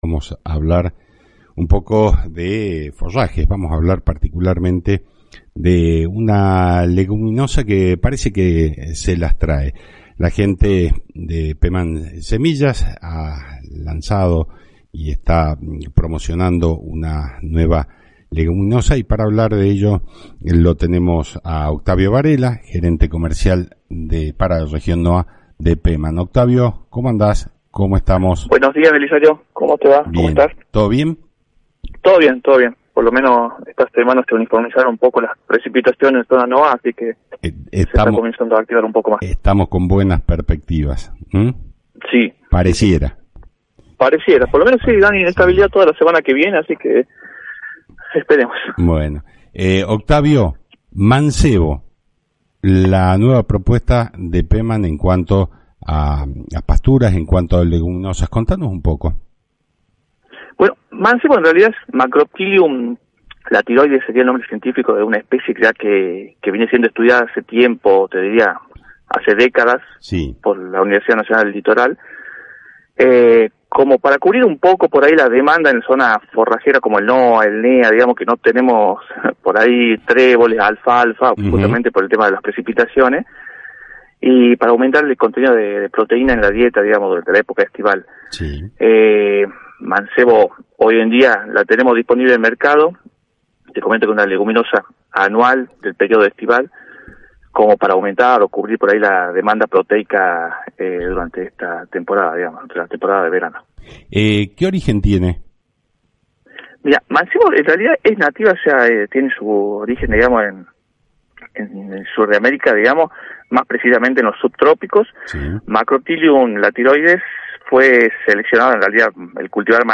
En el Programa Claves del Campo (AM 840 – Radio Salta)